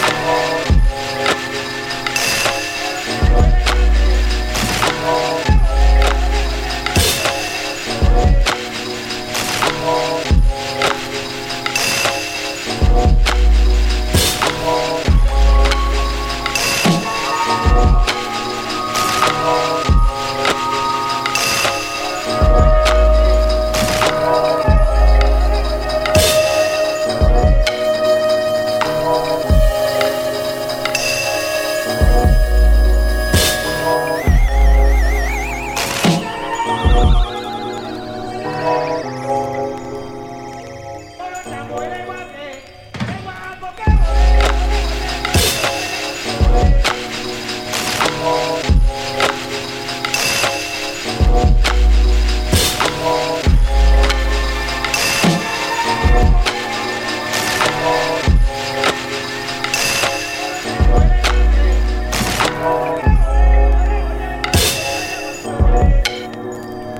70年代ジャズ＆ソウルの膨大なサンプルをカットアップ、要所でエフェクトや波形編集によるトリッキーな小技も魅せる全7曲！